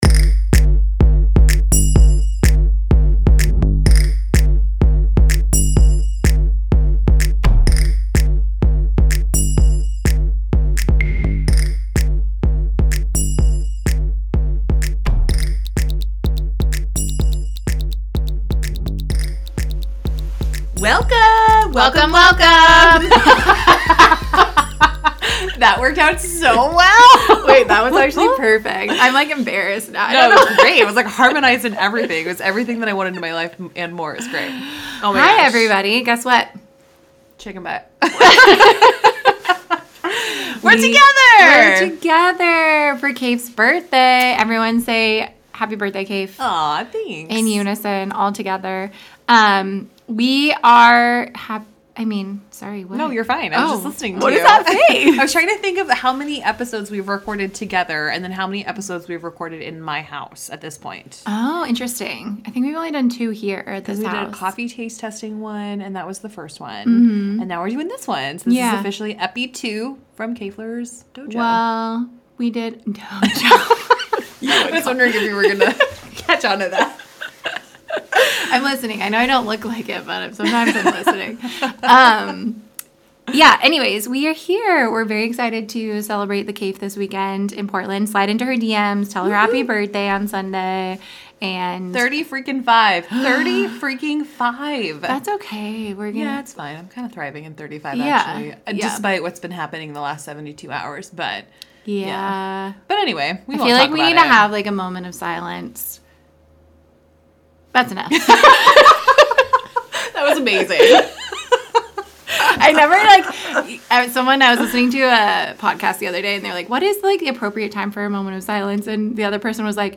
Ladies are talking about the hot topic of November 5th.